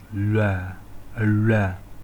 Approximants labzd alveolar lateral approximant
[lʷ] Lao
Labialized_alveolar_lateral_approximant.ogg.mp3